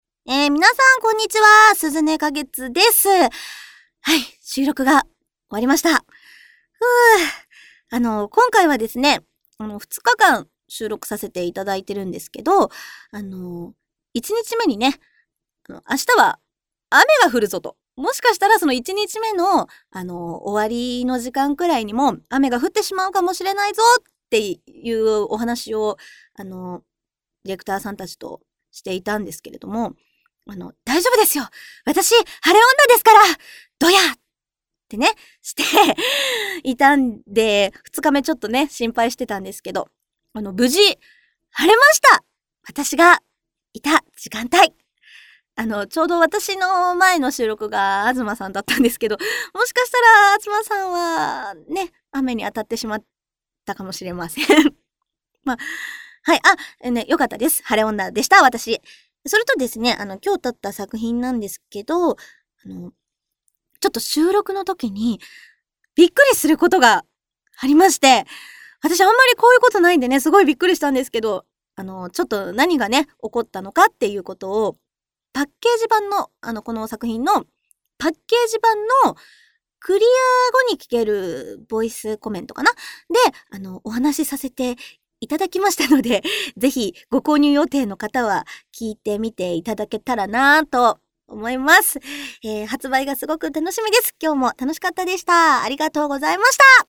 さて、結構量書いた割には内容が薄いですがシステム関係はここらで切り上げ、みなさまお待ちかねの声優さんのコメントです！